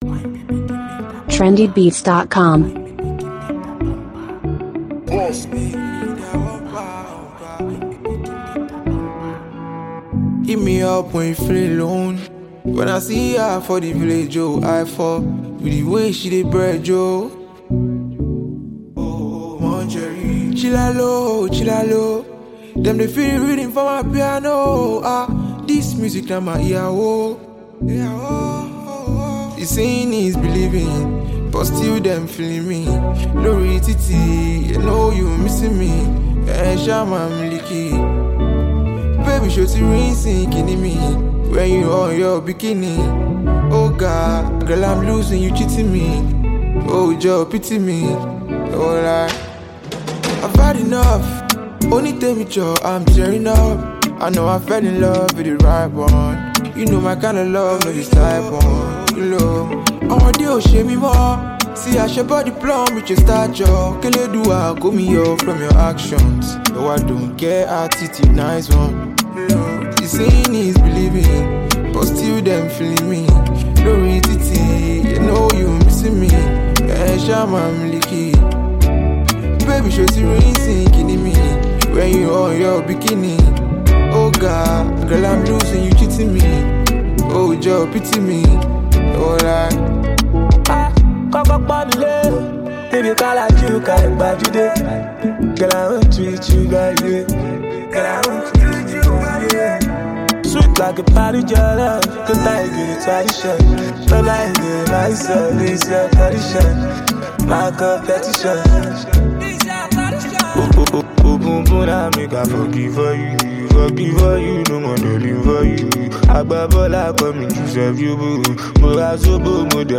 Fast-rising Nigerian Afrobeat music singer
He came through with catchy lyrics, and chorus
gave a sultry vibe to the song
infectious beats and melodies